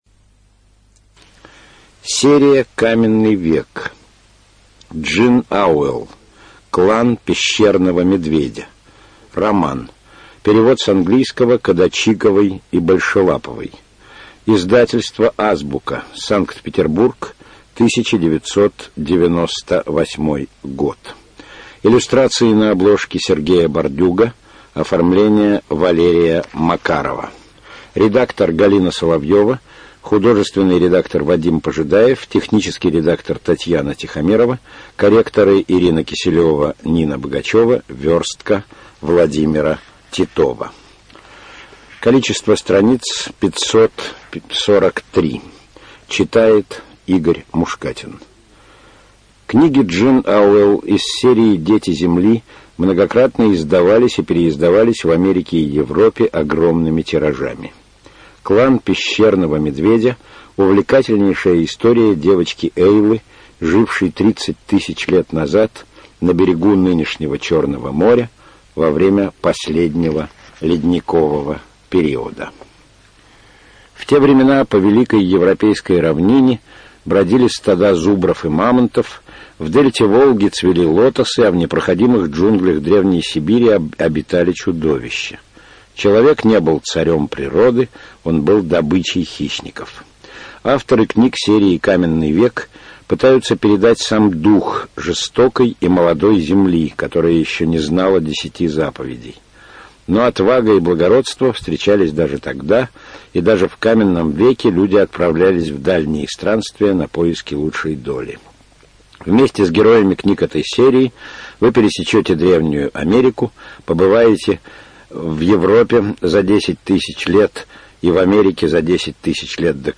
Студия звукозаписиИзраильская центральная государственная библиотека для незрячих и слабовидящих